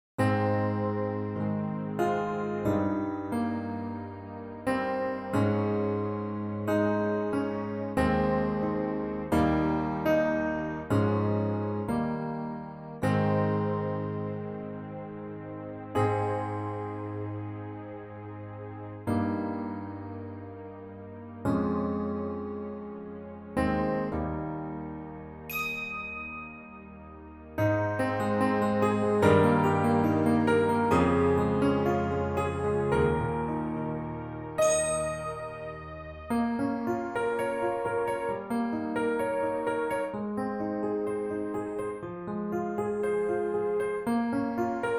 This is a professional performance track